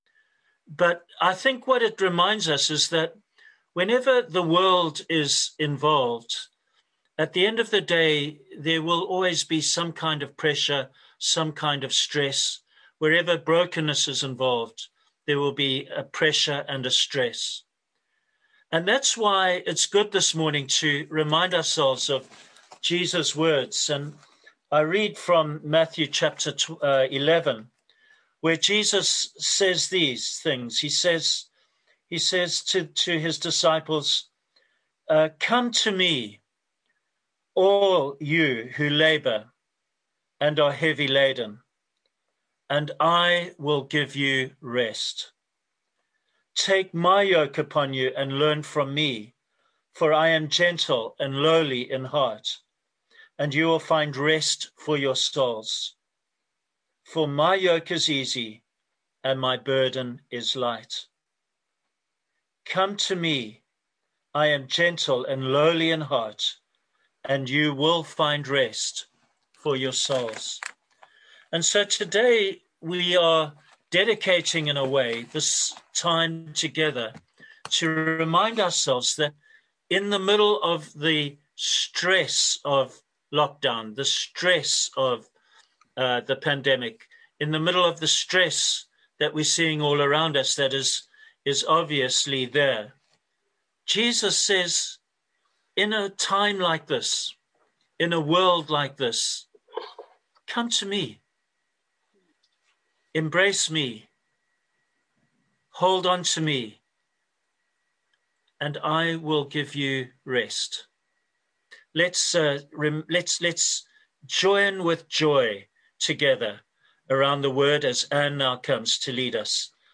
Therefore the decision to continue with the live-stream services online was taken.
Below is the recording of the sermon for this week.